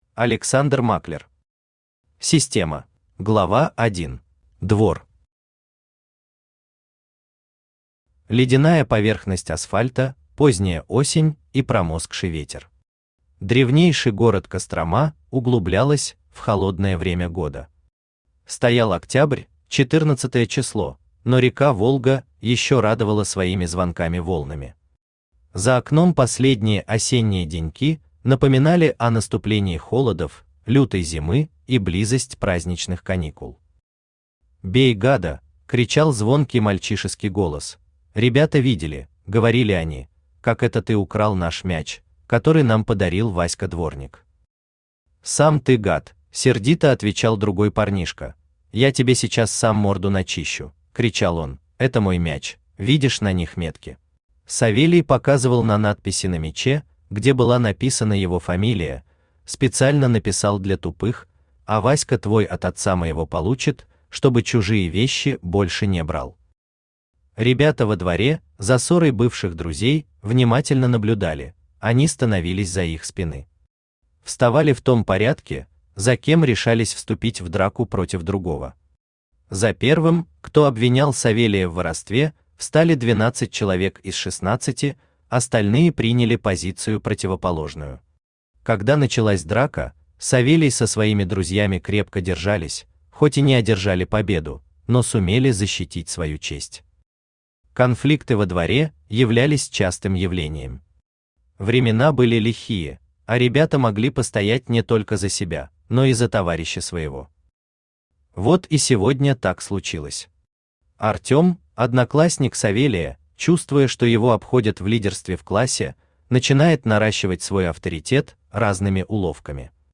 Аудиокнига Система | Библиотека аудиокниг
Aудиокнига Система Автор Александр Германович Маклер Читает аудиокнигу Авточтец ЛитРес.